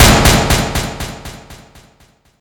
Gunshot.ogg